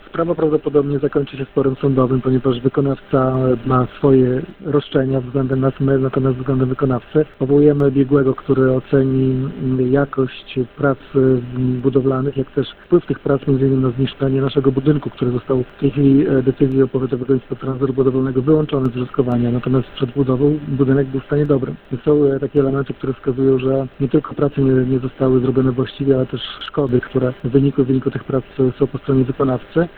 – Miasto najprawdopodobniej pójdzie do sądu z wykonawcą prac – mówi burmistrz Giżycka Wojciech Karol Iwaszkiewicz.